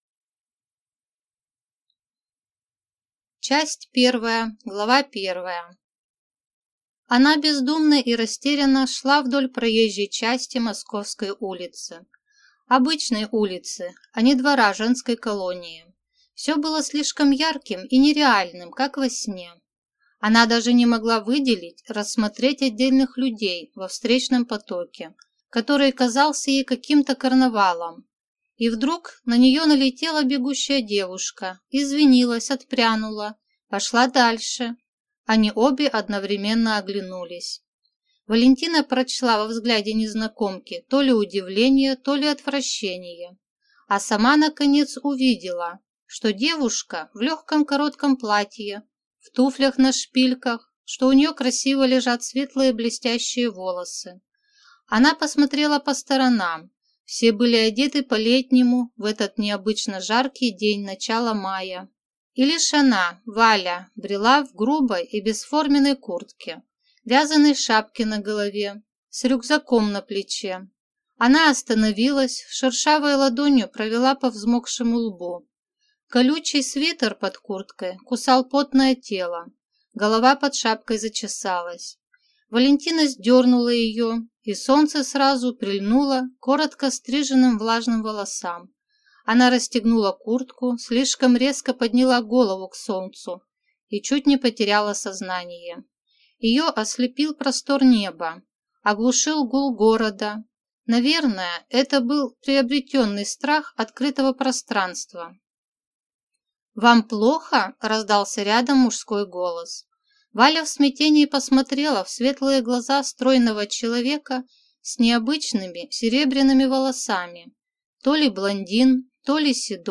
Аудиокнига Как свежи были розы в аду | Библиотека аудиокниг